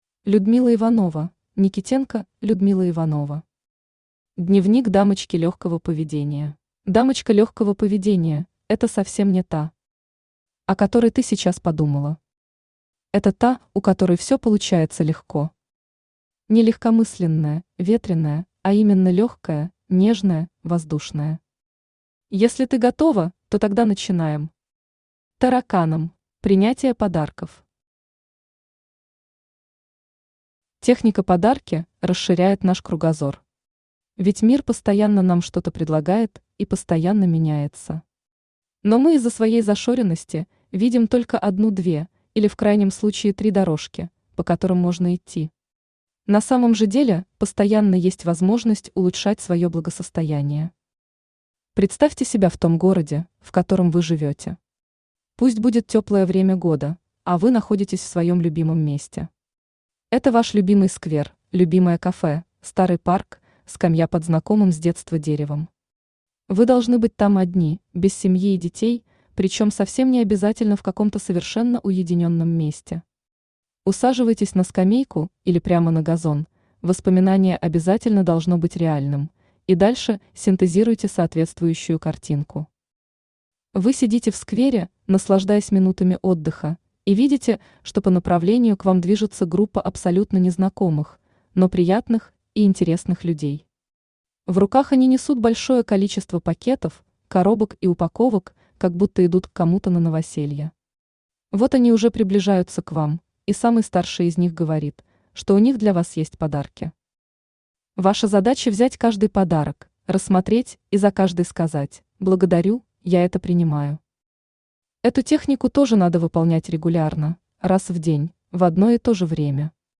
Аудиокнига Дневник дамочки легкого поведения | Библиотека аудиокниг
Aудиокнига Дневник дамочки легкого поведения Автор Людмила Геннадьевна Иванова Читает аудиокнигу Авточтец ЛитРес.